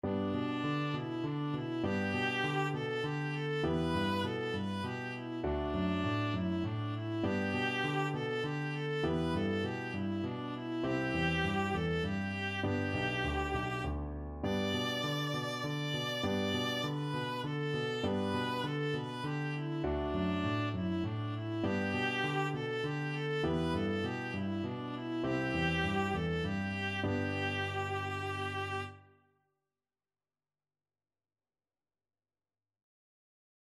D5-D6
3/4 (View more 3/4 Music)